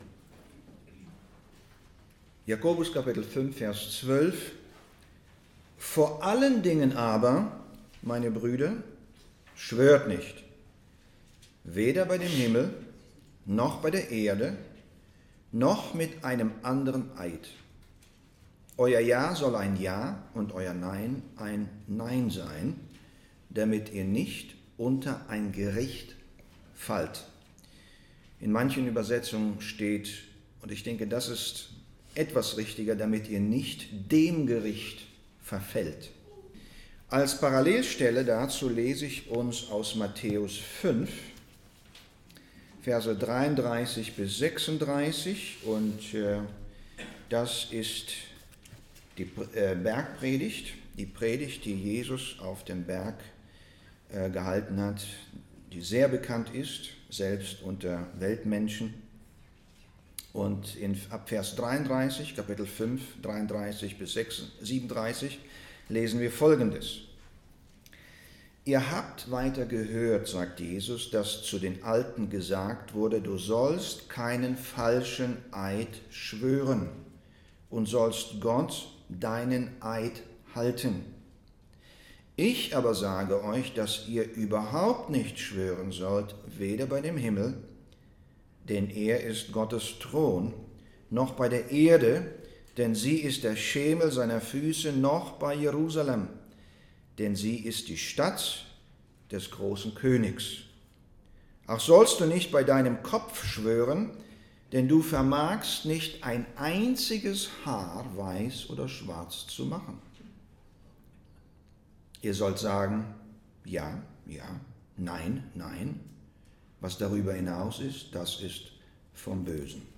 2025 Current Sermon Vor allem aber ...